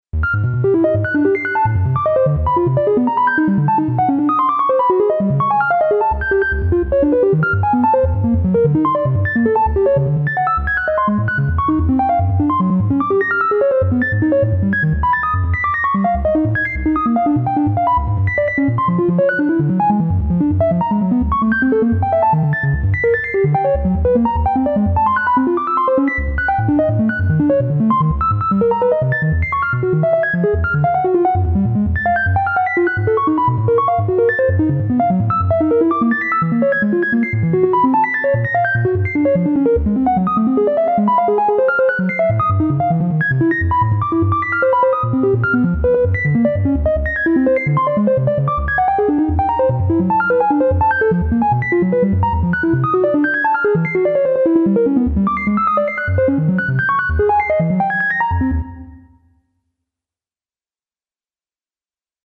イメージ：電子音 ランダム   カテゴリ：ユニーク・怪しい